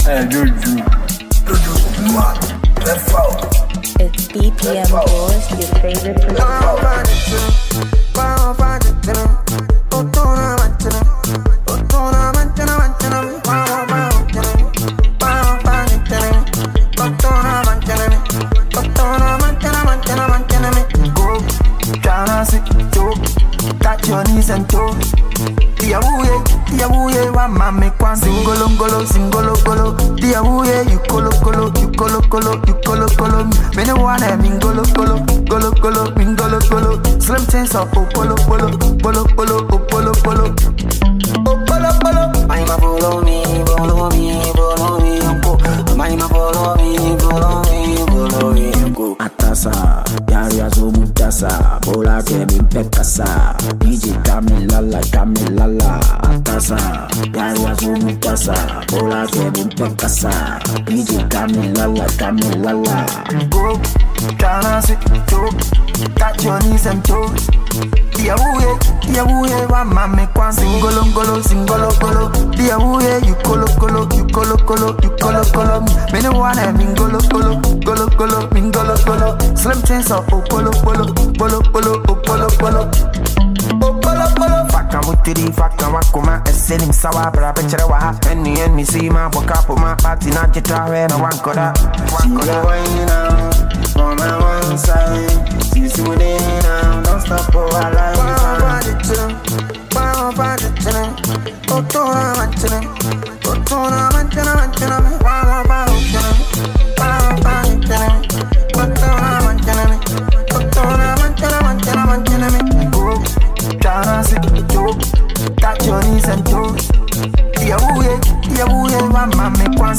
Ghanaian hardcore rapper
is built around a vibrant, feel-good vibe